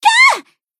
BA_V_Pina_Battle_Damage_3.ogg